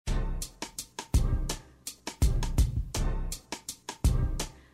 12-spot-hip-hop.mp3